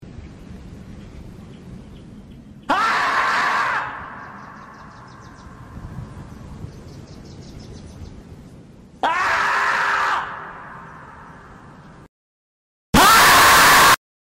castor-gritando.mp3